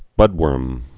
(bŭdwûrm)